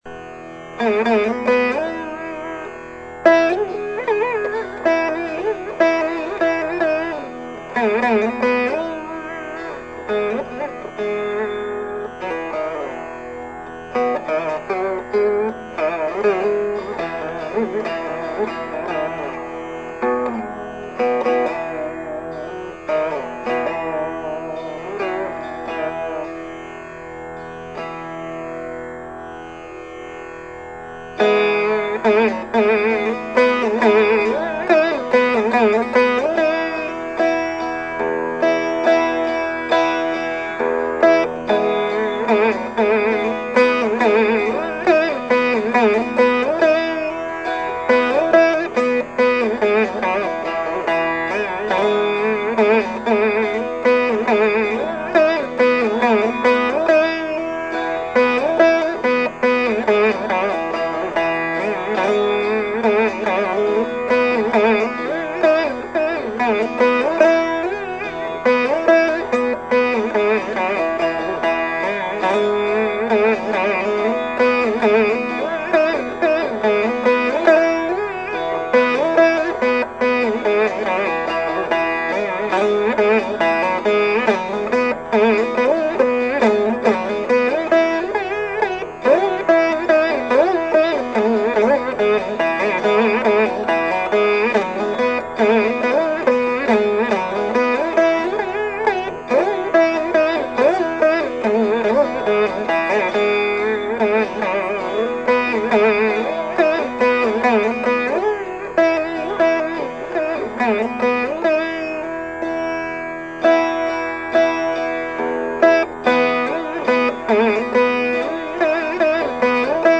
インド人ヴィーナ奏者
Pahi pahi bala ganapathe」〜ガネーシャへの讃歌